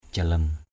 jalem.mp3